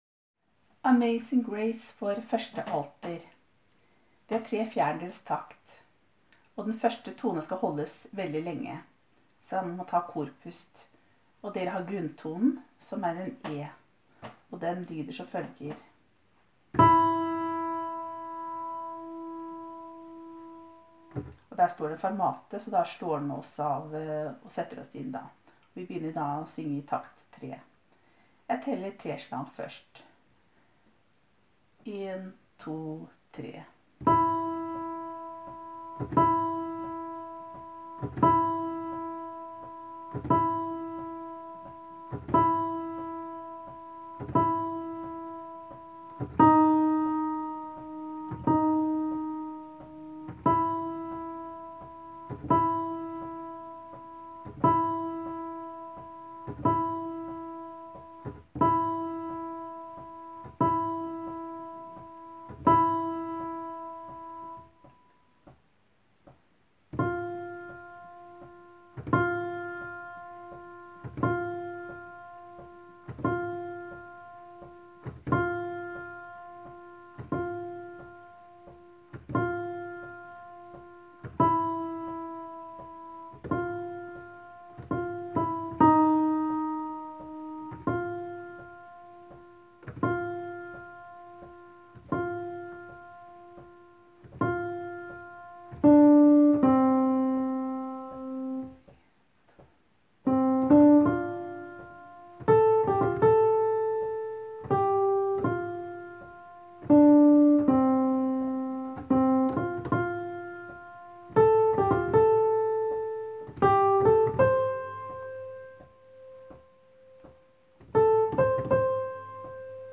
Vil du øve med musikk/konsertopptak finner du alle filene her (uten noen pianostemmer):
1.Alt Amazing grace: